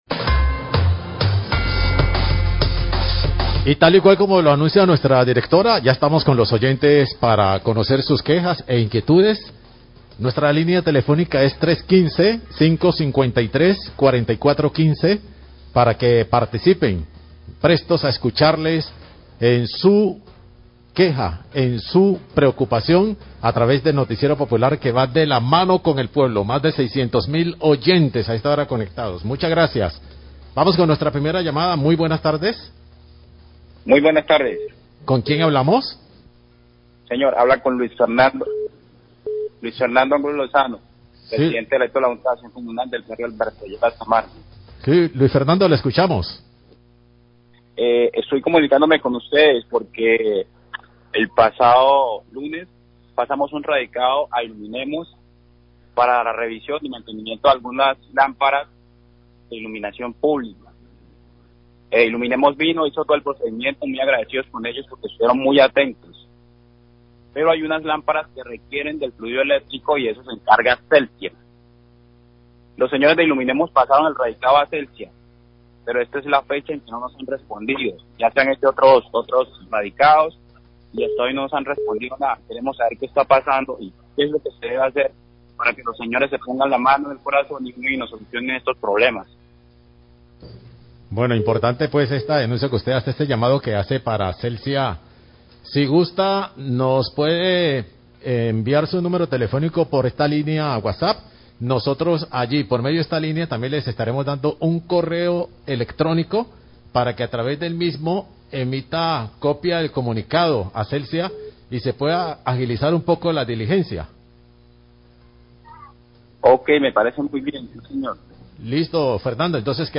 Radio
pdte JAC del barrio ...(es inaudible el audio en ese momento) se queja porque Iluminemos atendió daños de lámparas del alumbrado público pero otras no cuentan con el servicio de energía.